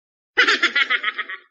Sound Effects
Weird Laugh Epic